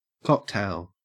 cocktail-au.mp3